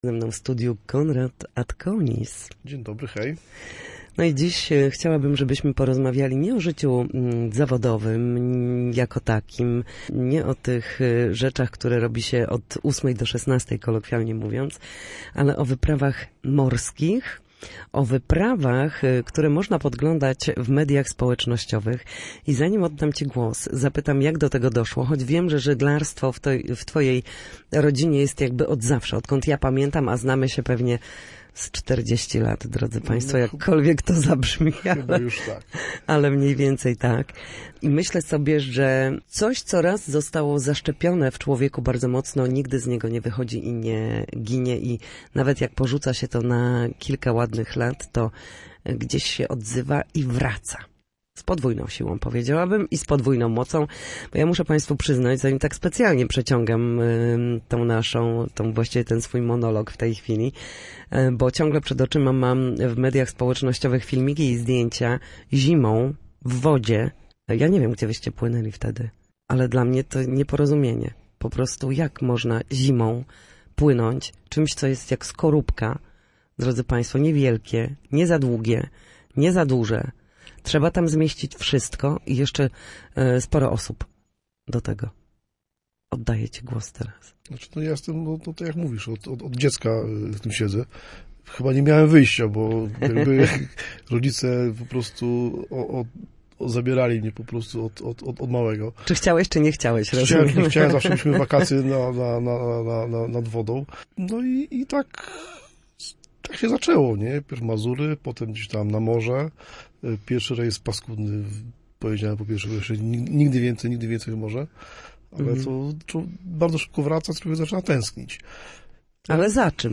Posłuchaj rozmowy z człowiekiem, który żeglowanie poza sezonem traktuje jako najlepszą szkołę charakteru, bez tłumów i utartych schematów, a organizowane przez niego wyprawy łączą przygodę z solidnym szkoleniem i prawdziwym doświadczeniem morza.